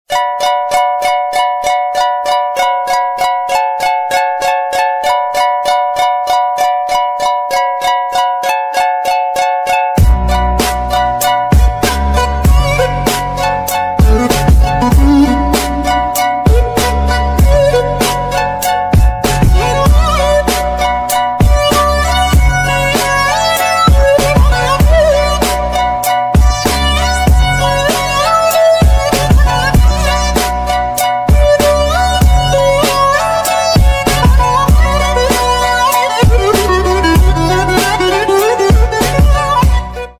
Nice Music (Instrumental)